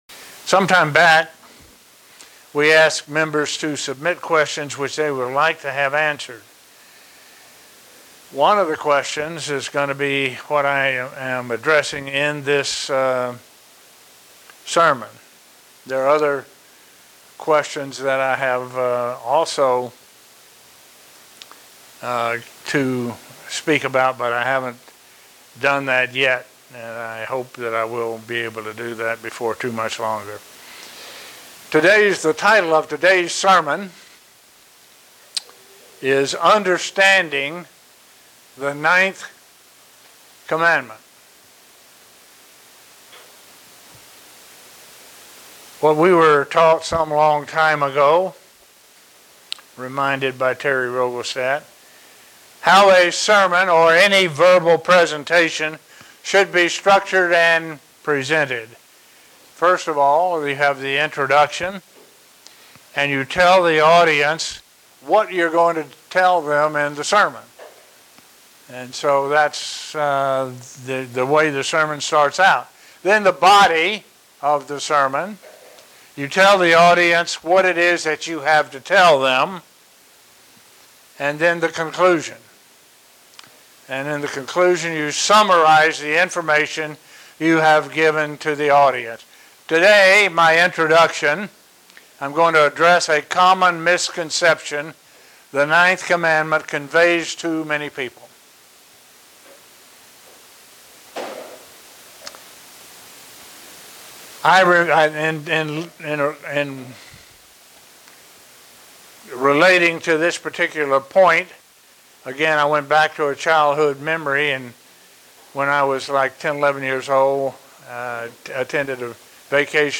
Given in Buffalo, NY